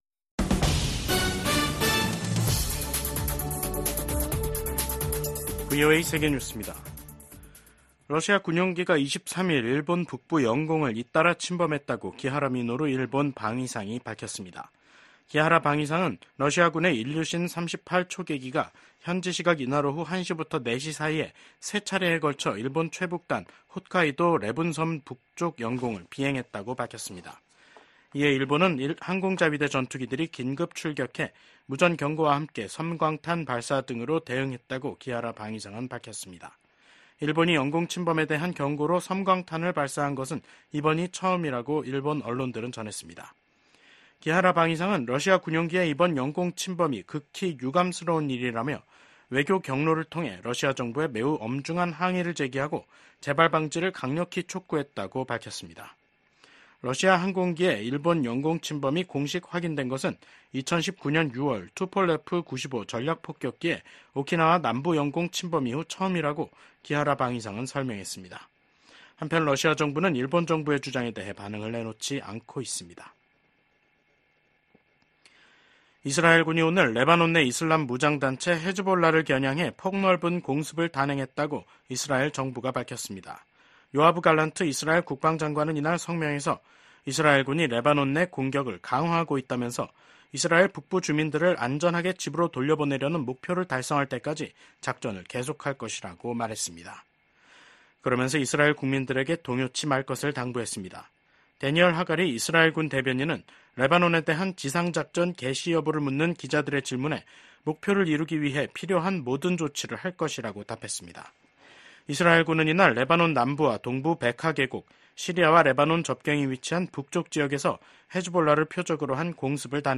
VOA 한국어 간판 뉴스 프로그램 '뉴스 투데이', 2024년 9월 20일 3부 방송입니다. 미국과 일본, 호주, 인도 정상들이 북한의 미사일 발사와 핵무기 추구를 규탄했습니다. 미국 정부가 전쟁포로 실종자 인식의 날을 맞아 미군 참전용사를 반드시 가족의 품으로 돌려보낼 것이라고 강조했습니다. 유엔 북한인권특별보고관이 주민에 대한 통제 강화 등 북한의 인권 실태가 더욱 열악해지고 있다는 평가를 냈습니다.